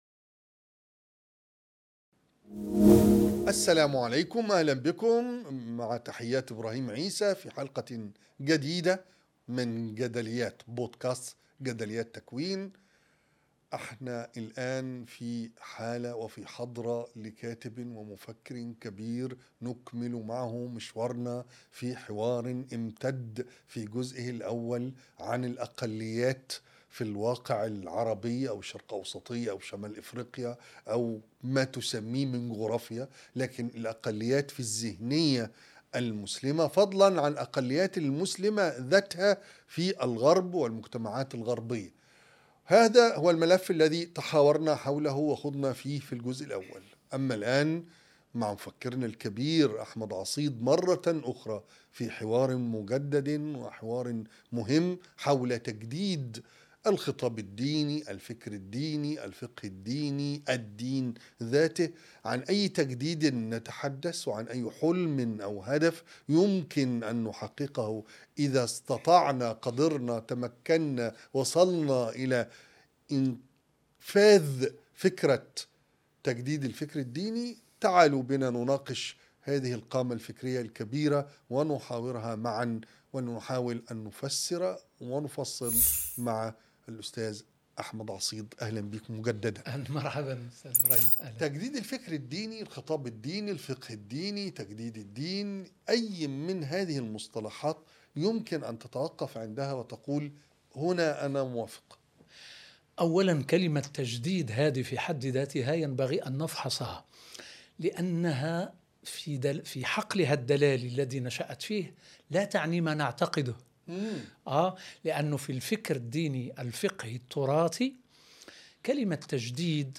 حوار مفتوح مع المفكر المغربي أحمد عصيد- الجزء الثاني
كيف يمكننا أن ننجح في الإصلاح في المجتمعات العربية؟ وكيف يمكننا اللحاق بركب الحضارة؟ وماذا يمكن أن نستخلص من قصة الإصلاح الديني في أوروبا؟ كل هذه الأسئلة وغيرها يجيب عنها المفكر المغربي أحمد عصيد في بودكاست جدليات مع إبراهيم عيسى